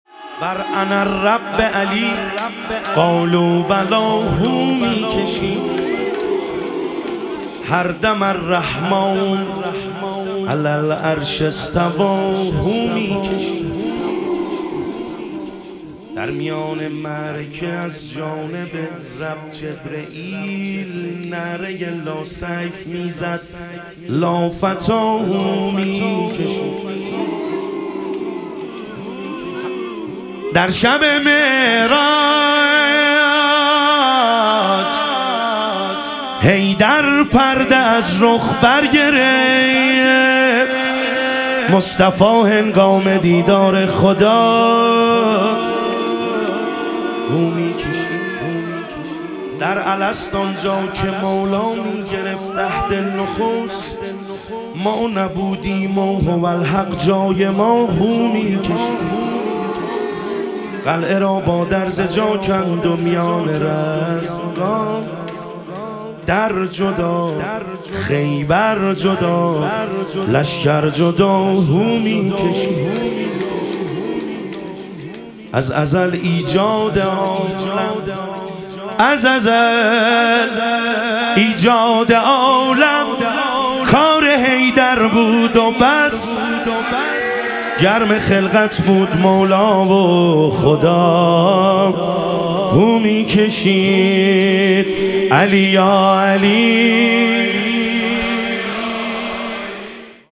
شهادت حضرت ام البنین علیها سلام - مدح و رجز